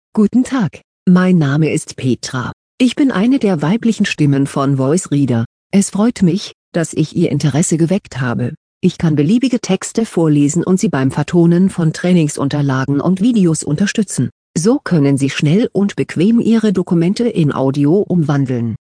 Voice Reader Home 22 Deutsch - Weibliche Stimme [Petra] / German - Female voice [Petra]
Voice Reader Home 22 ist die Sprachausgabe, mit verbesserten, verblüffend natürlich klingenden Stimmen für private Anwender.